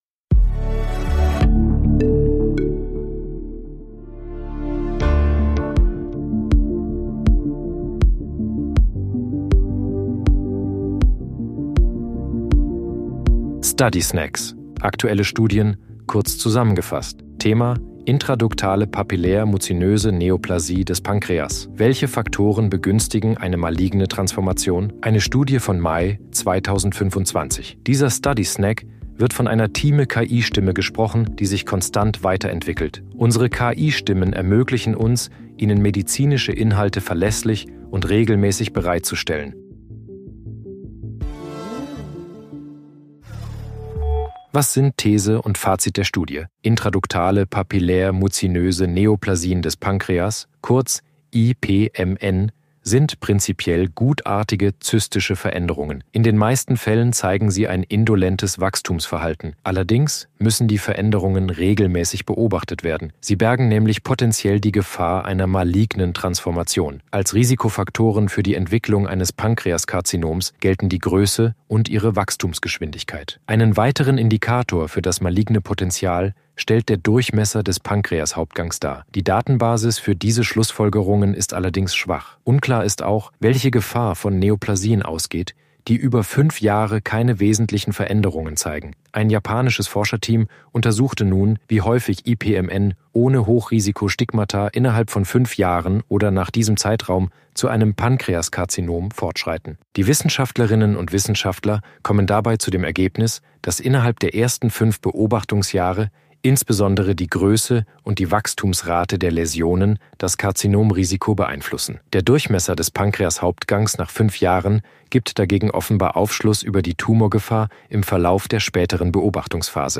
sind mit Hilfe von künstlicher Intelligenz (KI) oder maschineller
Übersetzungstechnologie gesprochene Texte enthalten